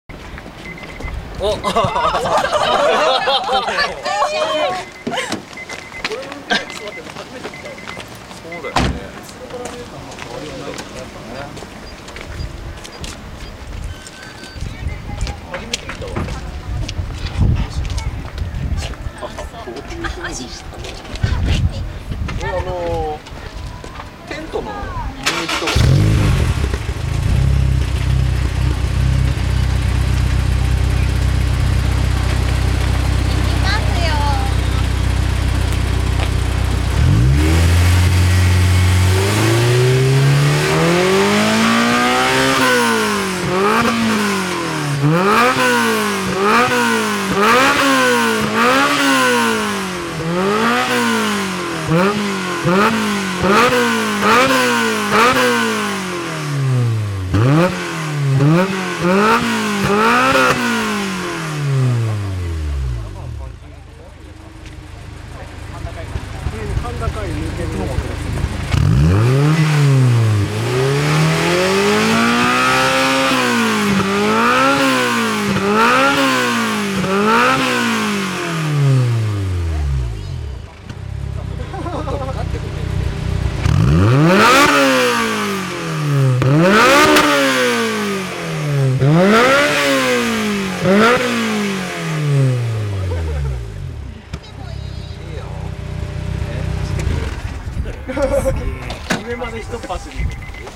(今回はS2000マフラーサウンド録音オフだよ〜）
マイク：業務用ガンマイク
『※ぴんぽ〜ん 　今回は空ぶかしメインで〜す(^◇^;)』